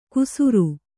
♪ kusuru